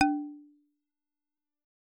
content/hifi-public/sounds/Xylophone/D2.L.wav at f5a04026fc8591c53cf9c5357bef5512c6f47b51